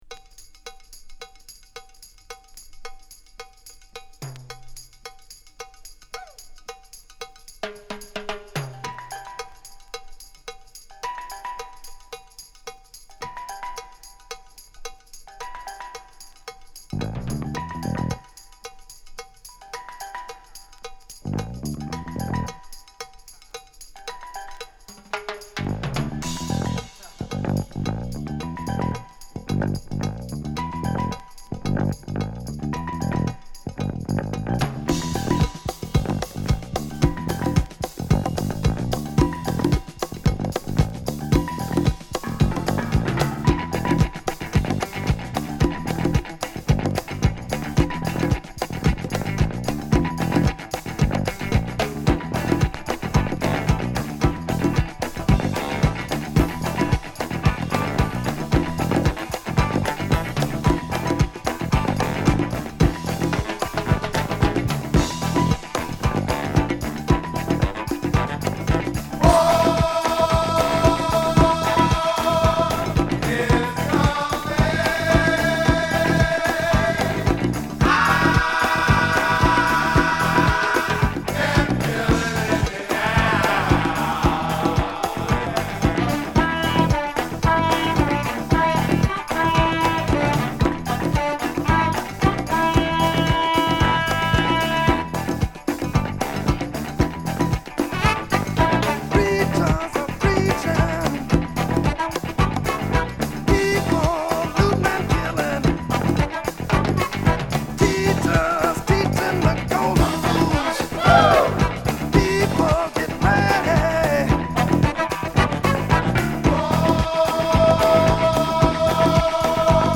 西海岸の空気感が気持ち良い、陽だまりのメロウチューンA3